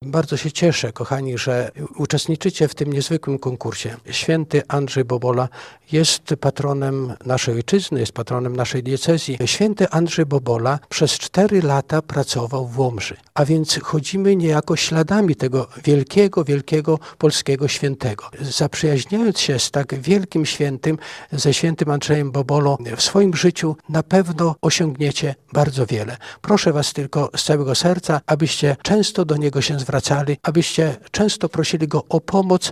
Finał odbył się w środę (15.04) w siedzibie Radia Nadzieja.
Podczas finału konkursu do młodych uczestników zwrócił się biskup Tadeusz Bronakowski, który podkreślił, że postać św. Andrzeja Boboli może być dla młodego pokolenia inspiracją: